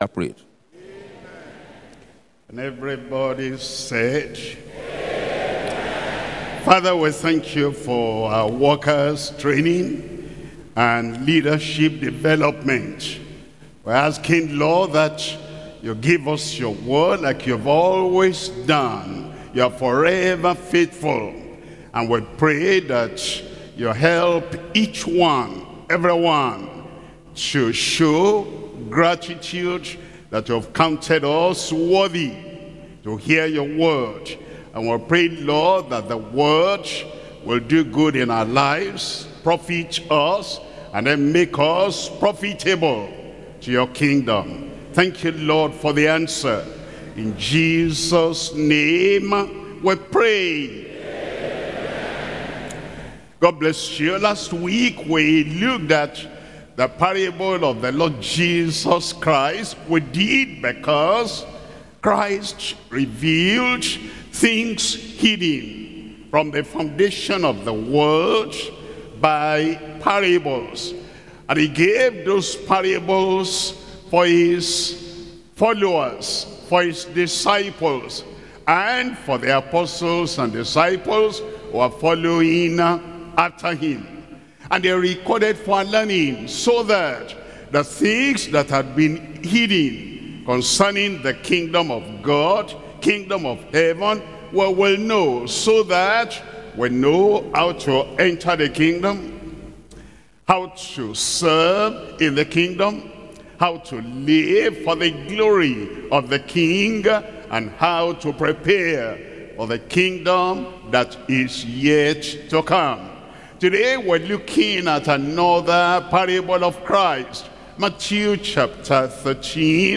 Sermons – Deeper Christian Life Ministry, United Kingdom